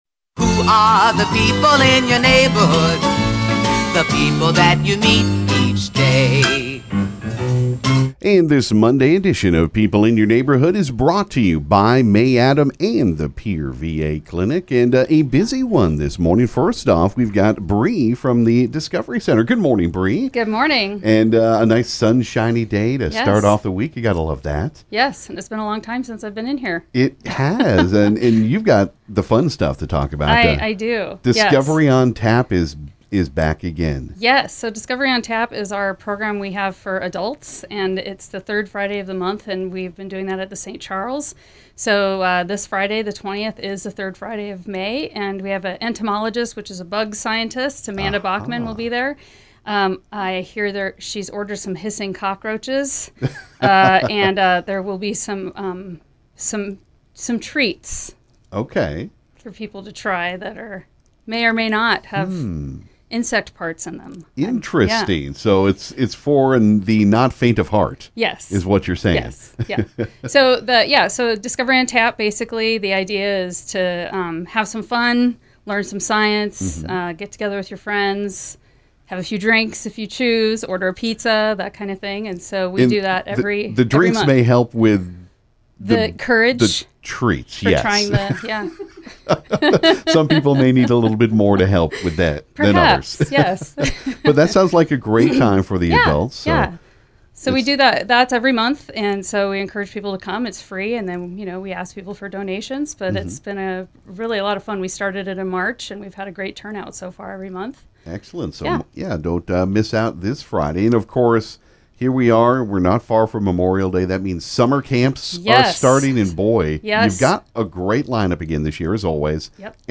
This morning was busy in the KGFX Studio for People In Your Neighborhood!